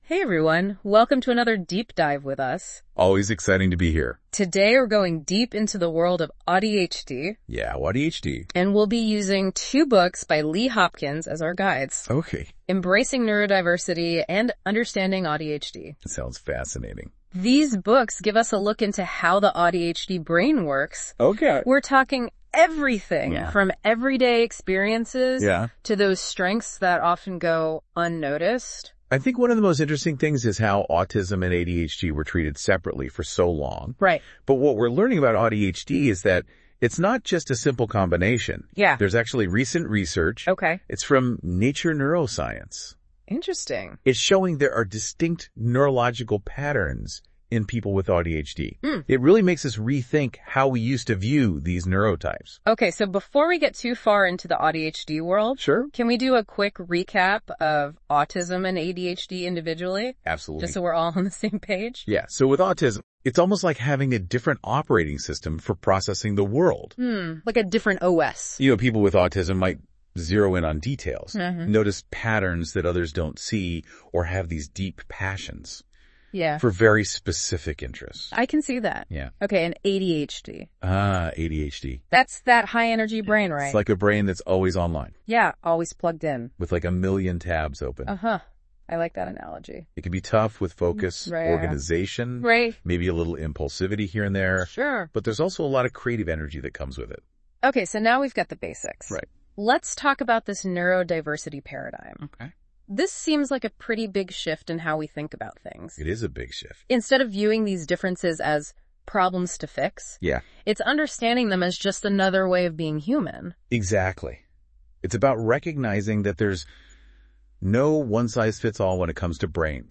Buy Embracing Neurodiversity :: Buy Understanding AuDHD You can listen to a discussion of both books from Google’s AI-driven Deep Dive podcast below.